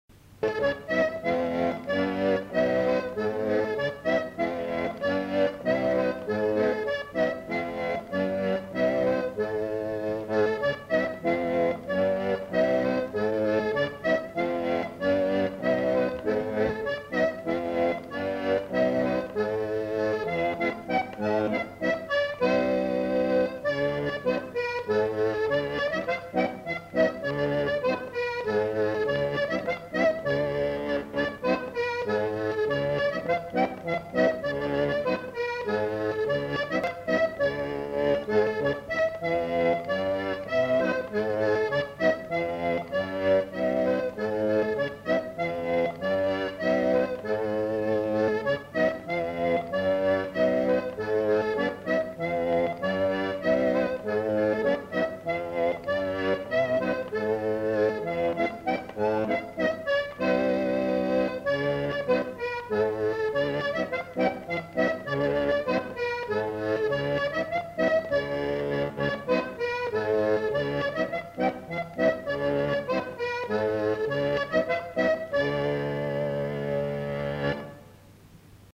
Instrumental. Accordéon diatonique. Bretagne
Aire culturelle : Bretagne
Genre : morceau instrumental
Instrument de musique : accordéon diatonique